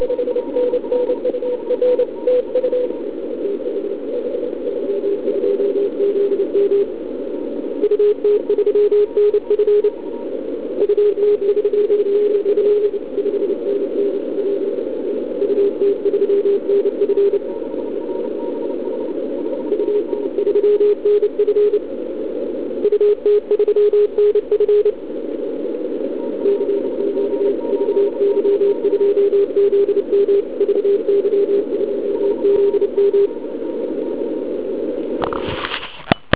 A jak je slyšet na 160m i na "pádlové" antény v OK? Našel jsem ve své sbírce několik nahrávek jak jsem ho slyšel já.
Úžasný signál.